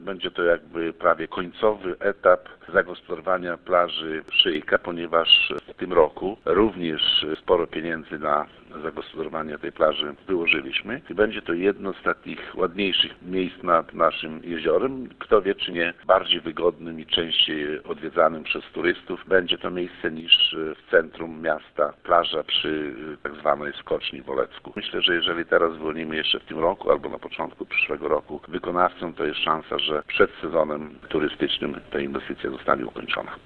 Jak dodaje Wacław Olszewski, nowa plaża może okazać się bardziej atrakcyjna obok tej w centrum Olecka.